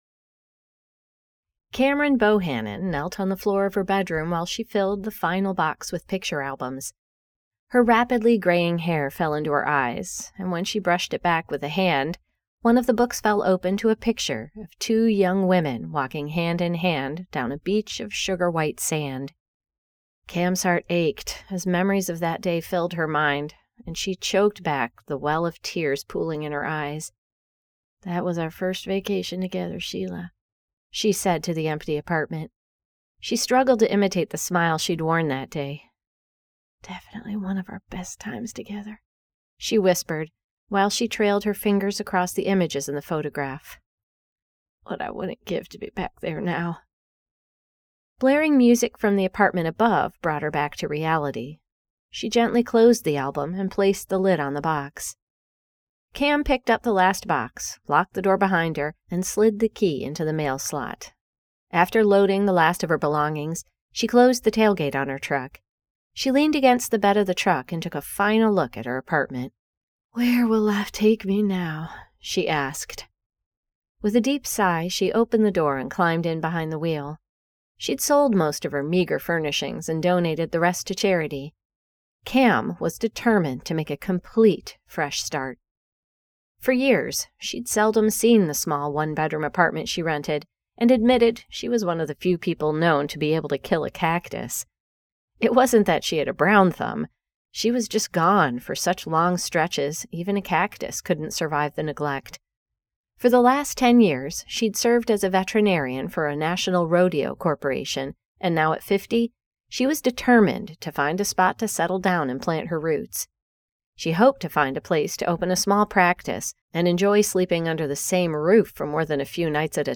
Back in the Saddle by Ali Spooner [Audiobook]